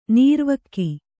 ♪ nīrvakki